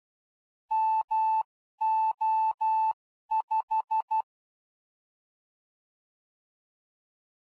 Fox hunt beacons are identified by morse code signals.
Morse code identifiers of the foxes
5 MO5 — — — — — ∙ ∙ ∙ ∙ ∙ [ogg][mp3]